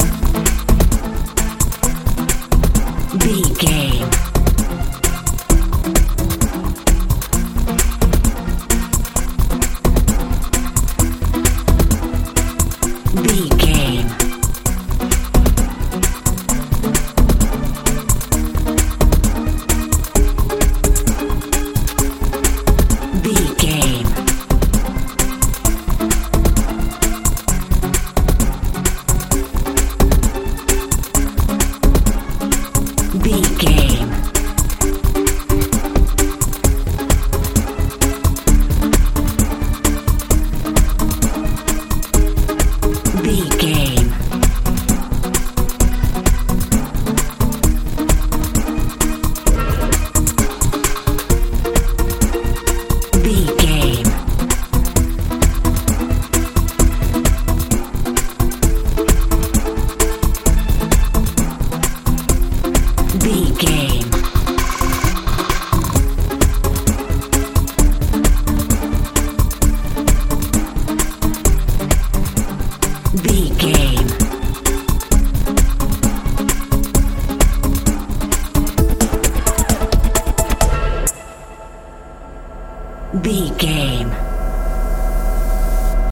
dance feel
Ionian/Major
magical
mystical
bass guitar
drums
synthesiser
80s
tension
suspense